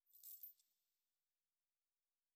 03_鬼差脚步_2.wav